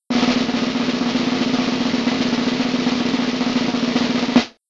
drum.wav